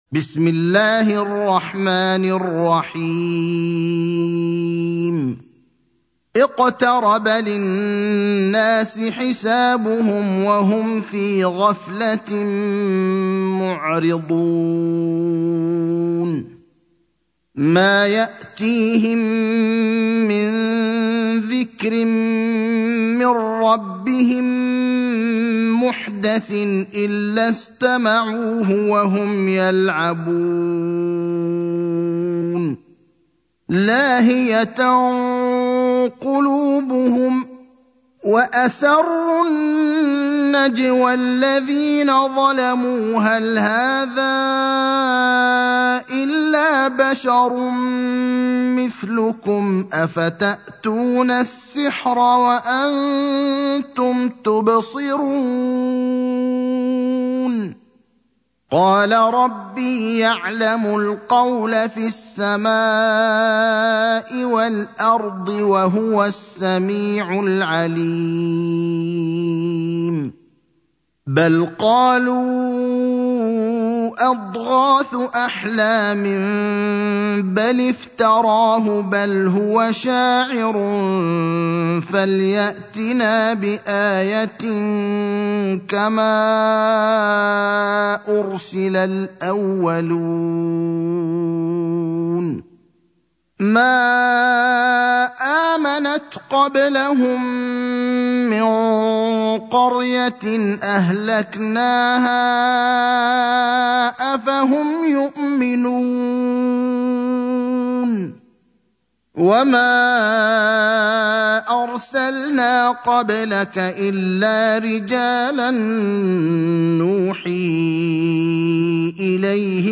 المكان: المسجد النبوي الشيخ: فضيلة الشيخ إبراهيم الأخضر فضيلة الشيخ إبراهيم الأخضر الأنبياء (21)